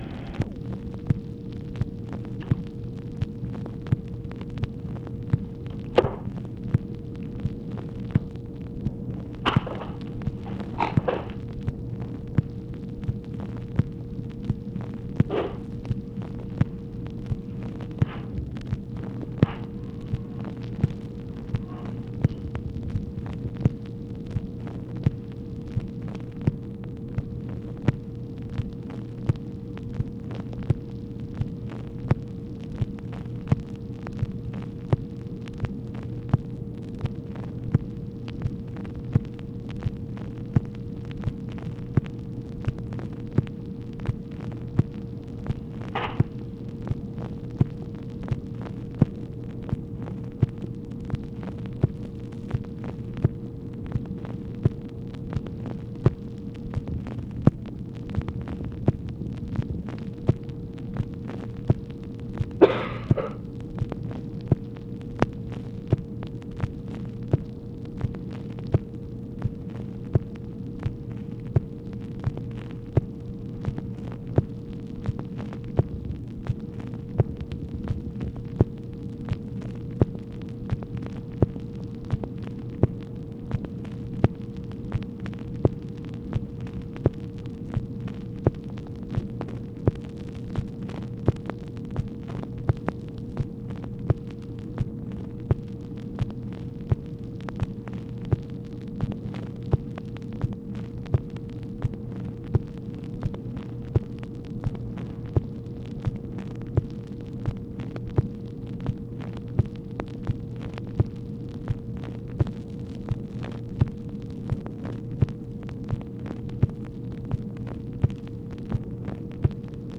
OFFICE NOISE, May 2, 1965